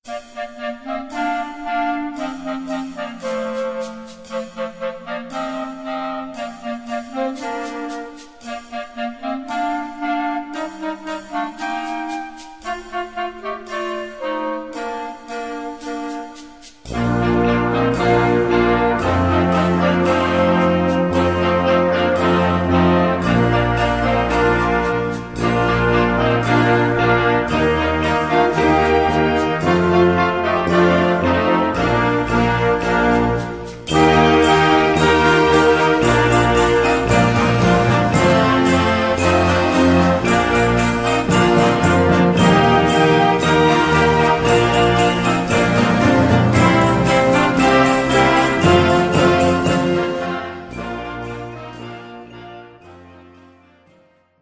Gattung: Unterhaltungsmusik
Besetzung: Blasorchester